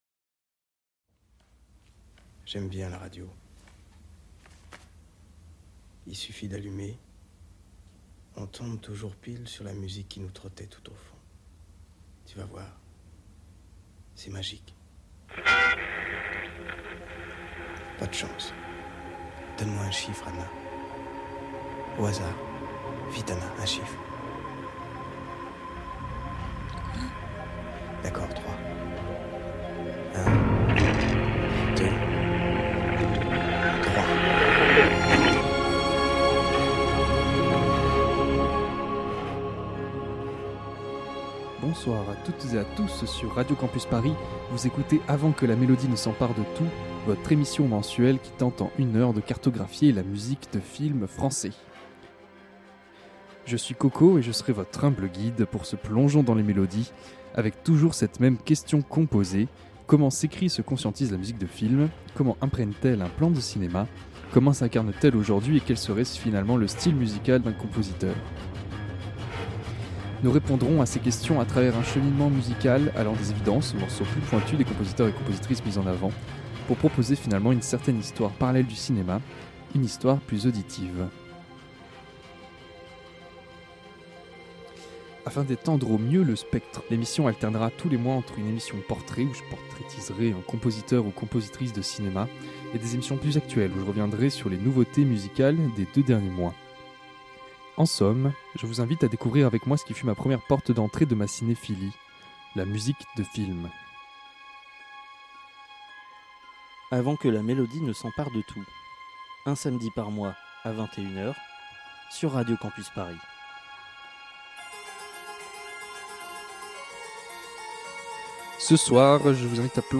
Type Musicale Classique & jazz